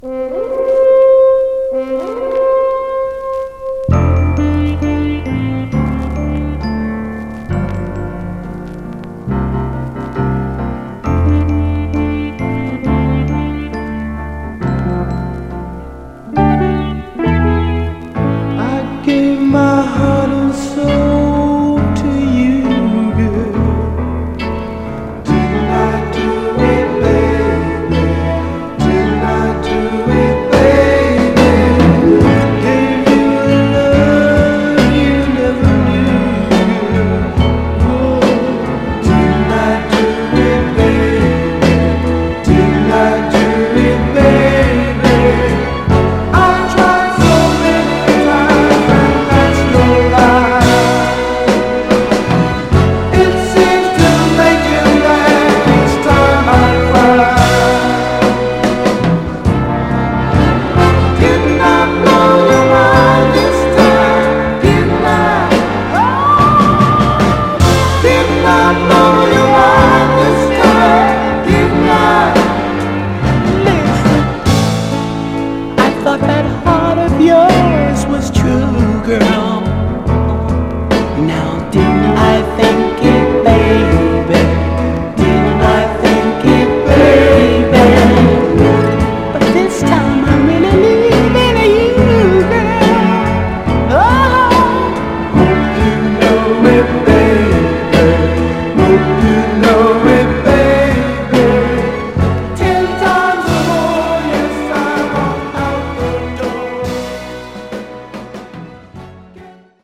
甘茶なスウィート・ソウル・クラシックです！
盤はエッジ中心に細かいごく表面スレ、エッジに僅かなくすみ箇所あり、イントロで僅かにジリっと鳴ります。
※試聴音源は実際にお送りする商品から録音したものです※